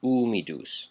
Ääntäminen
US : IPA : [ˈwɛt]